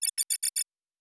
pgs/Assets/Audio/Sci-Fi Sounds/Interface/Data 29.wav at master